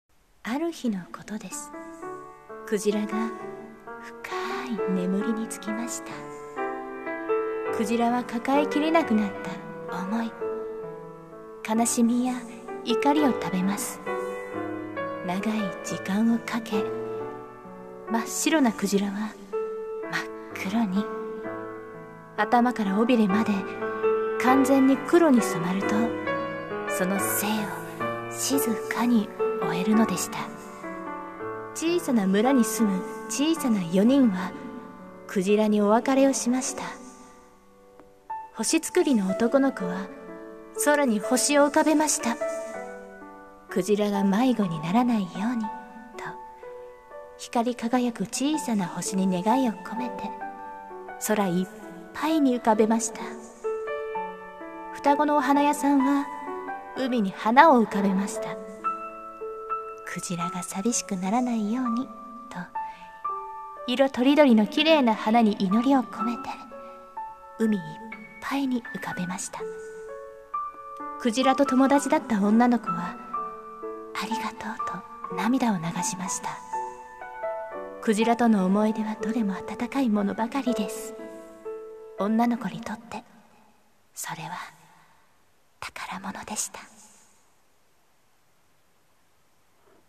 朗読･声劇